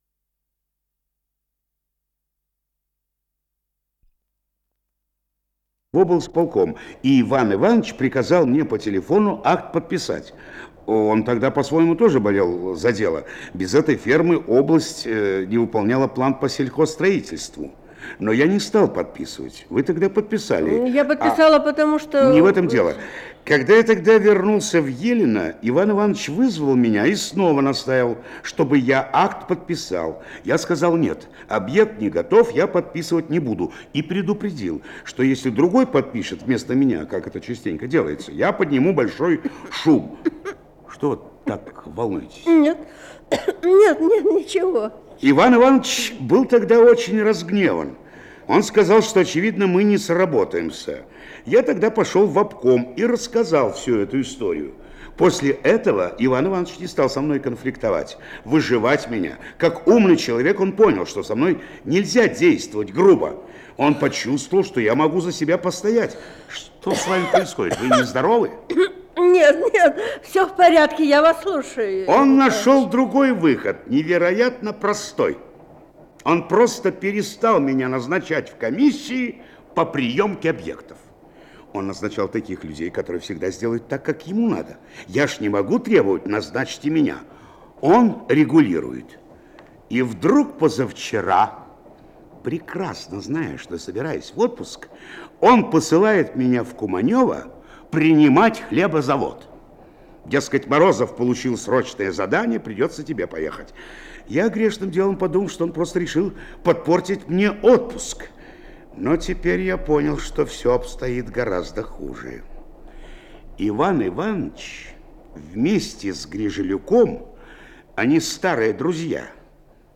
ЧС-016 — Отрывок неизвестной радиопостановки — Ретро-архив Аудио
Название передачи Отрывок неизвестной радиопостановки Код ЧС-016 Фонд Комитет по телевидению и радиовещанию Читинского облисполкома Редакция Литературная Общее звучание 00:14:50 Дата добавления 25.01.2023 Прослушать